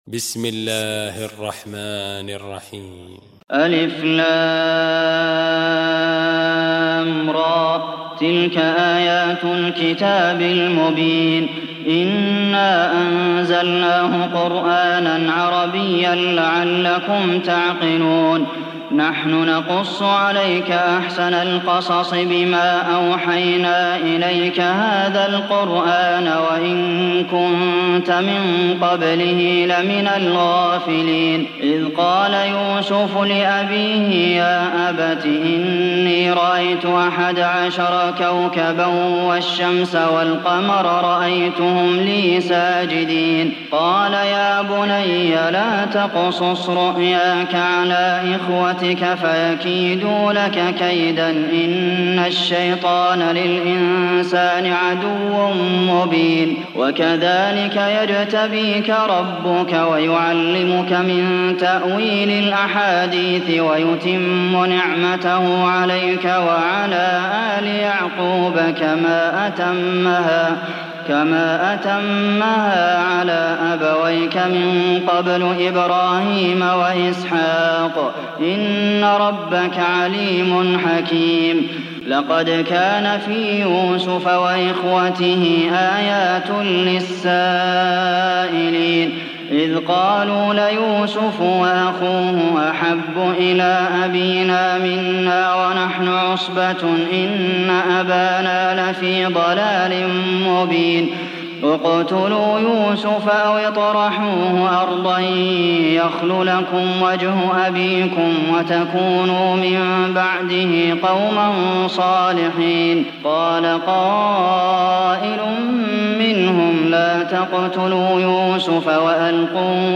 Sourate Yusuf Télécharger mp3 Abdulmohsen Al Qasim Riwayat Hafs an Assim, Téléchargez le Coran et écoutez les liens directs complets mp3